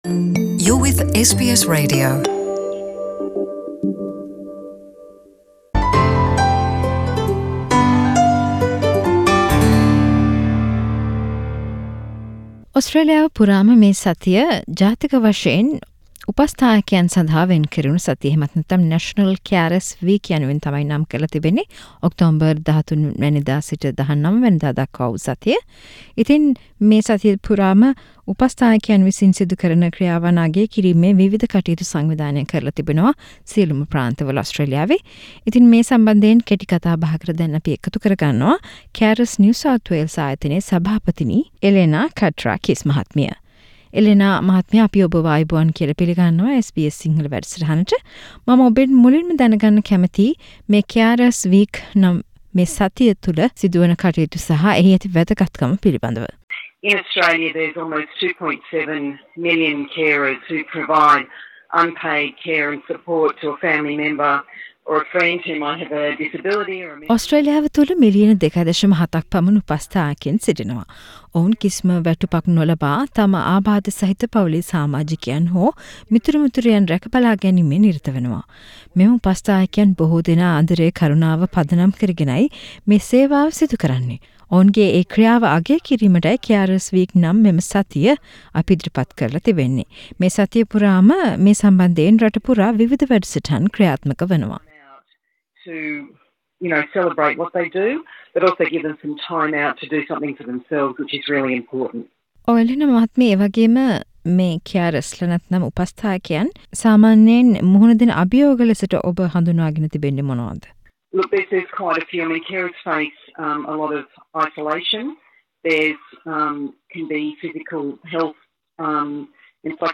සාකච්චාවක්